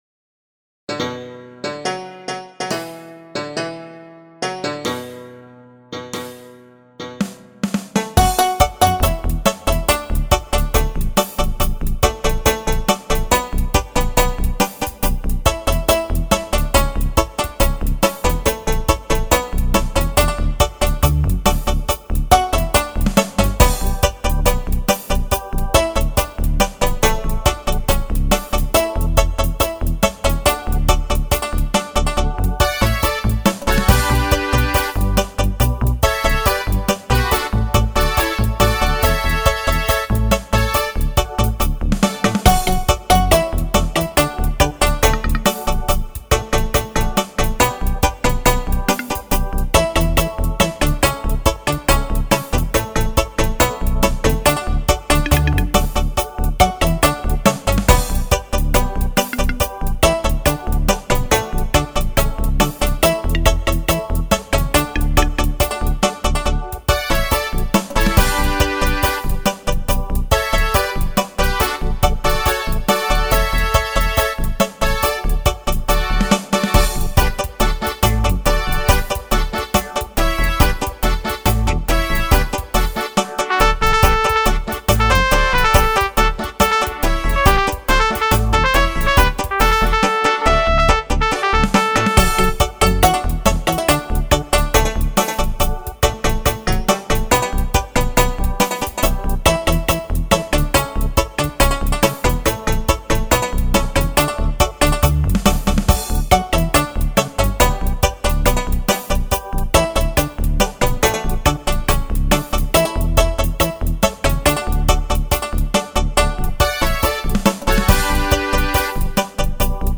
Jamaican Ska
Pop Reggae